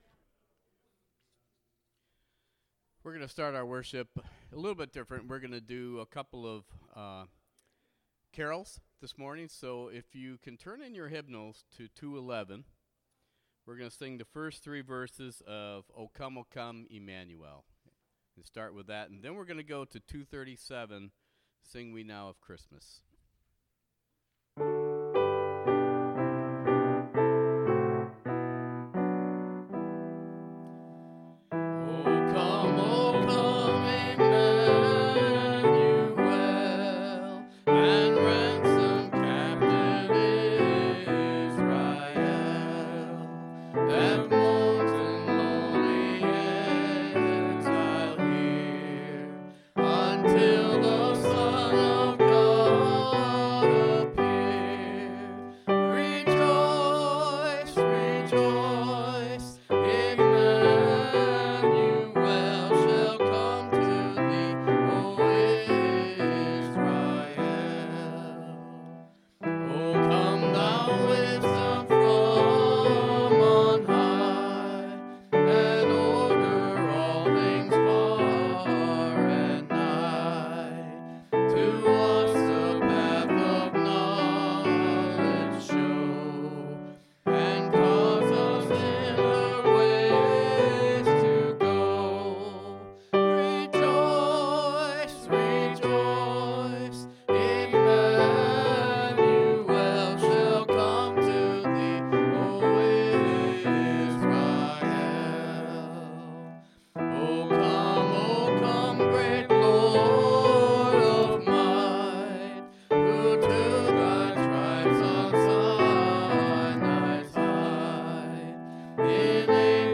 12-9-18 Sermon